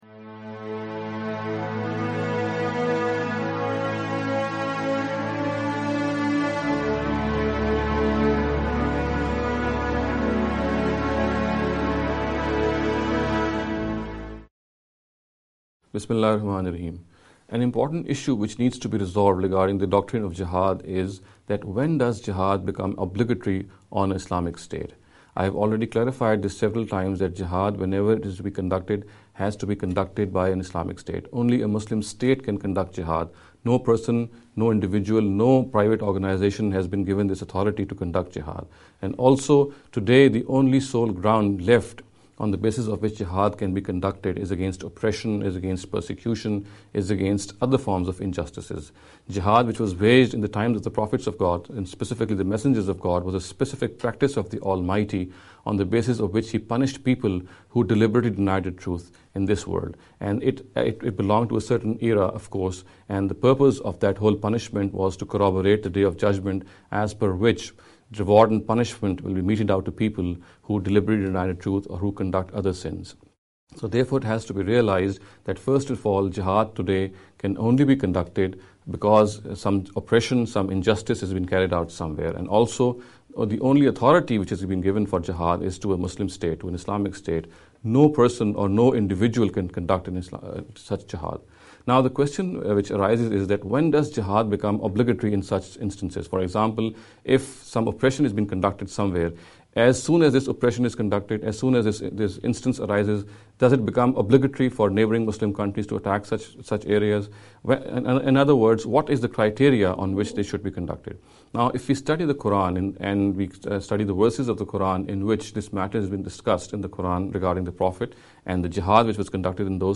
This lecture series will deal with some misconception regarding the Islam and Jihad.